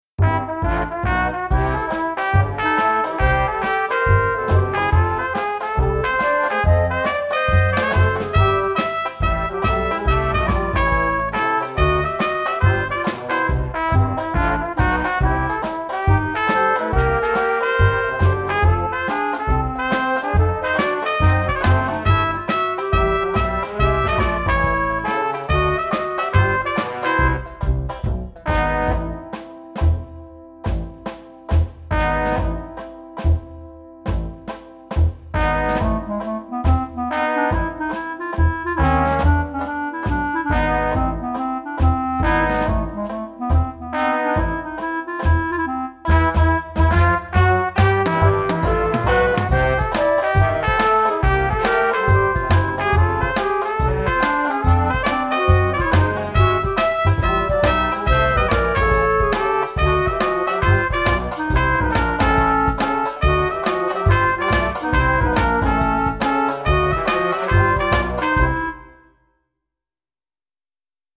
Roland Fantom XR Version